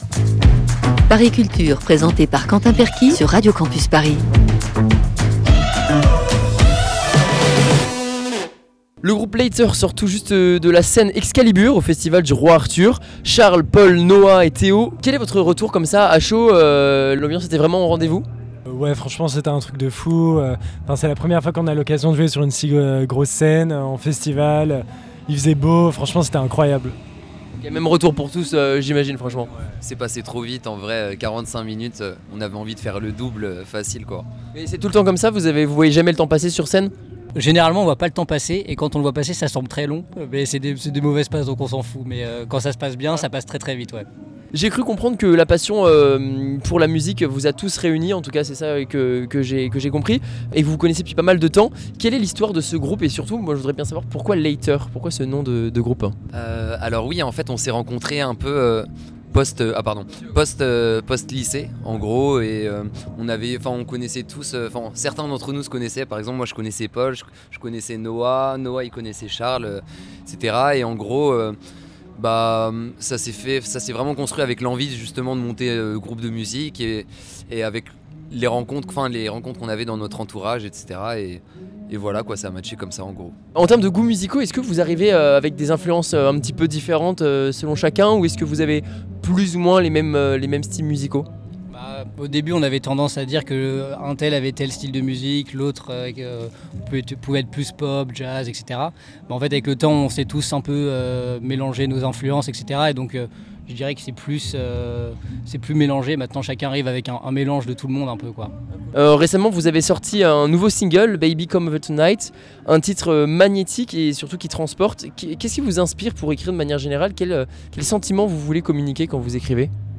Les membres du groupe LATER nous présentent leur univers, lors du festival du Roi Arthur cet été près de Rennes.